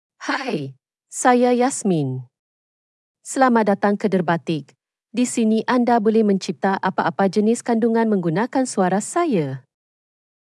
FemaleMalay (Malaysia)
YasminFemale Malay AI voice
Yasmin is a female AI voice for Malay (Malaysia).
Voice sample
Listen to Yasmin's female Malay voice.
Yasmin delivers clear pronunciation with authentic Malaysia Malay intonation, making your content sound professionally produced.